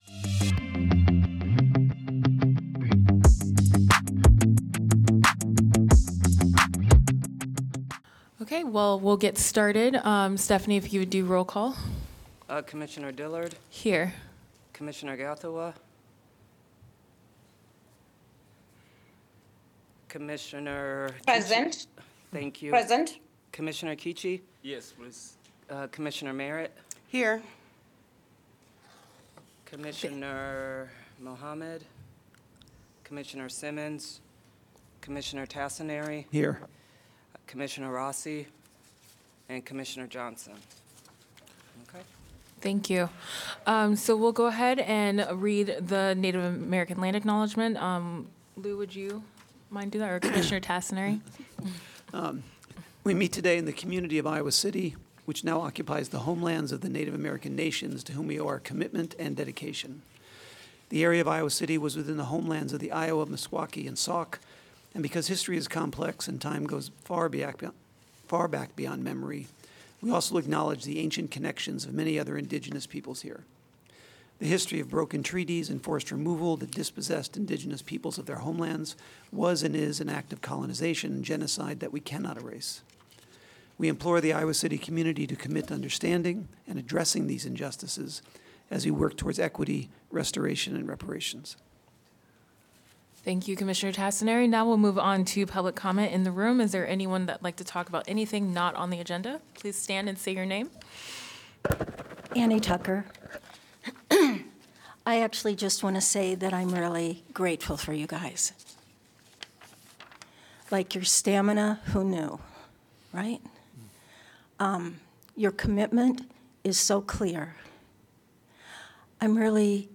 The final official meeting of the Ad Hoc Truth and Reconciliation Commission.